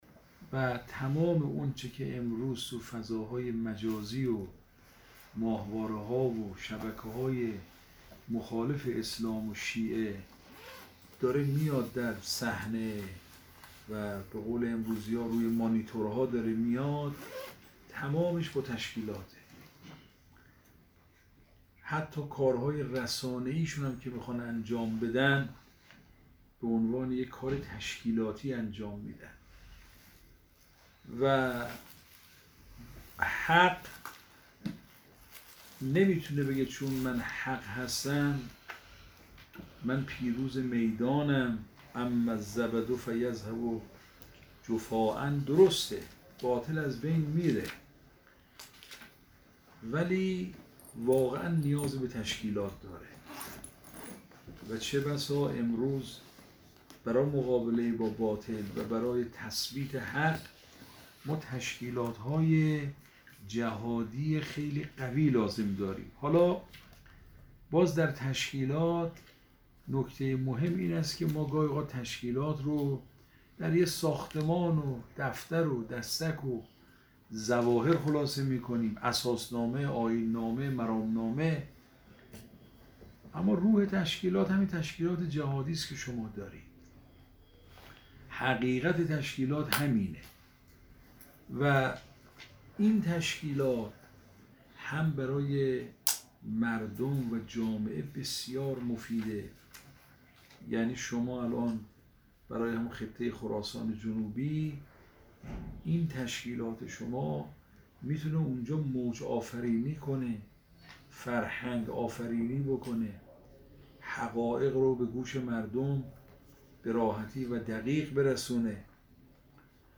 به گزارش خبرنگار خبرگزاری رسا، آیت الله محمدجواد فاضل‌لنکرانی استاد درس خارج حوزه علمیه قم امروز در دیدار با جمعی از طلاب و مبلغان خراسان جنوبی که در بیت مرحوم حضرت آیت الله فاضل‌لنکرانی برگزار شد، با اشاره به ارزش و اهمیت تبلیغ دین خاطرنشان کرد: با وجود فضاهای مجازی و القای شبهات و سؤالات، جای برای کار زیاد است.